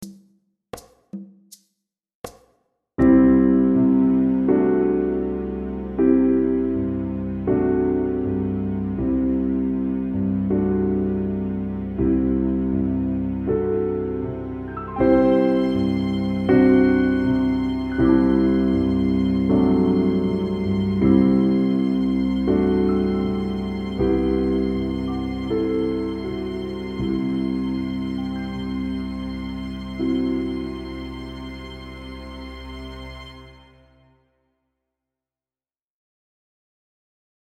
Tonalidade: do lidio; Compás: 4/4
Acompanhamento_2_Lidio.mp3